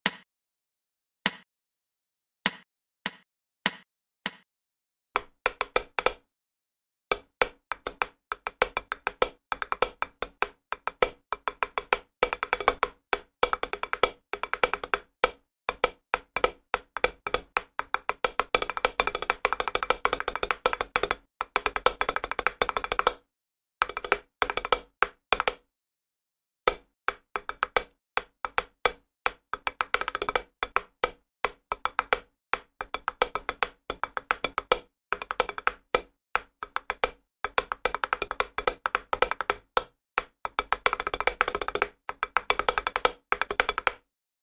Все этюды записаны на педе для большей разборчивости и возможно помогут тем кто занимается по указанной книге самостоятельно.
Этюд №11 - отрабатываем Seven Stroke Roll & Seven Stroke Ruff.
Размер 2/4.
Темп: первая часть ( такты 1-18 ) - 100 bpm, вторая (такты 19-34) - Con moto, 110 bpm.
Следует без повтора.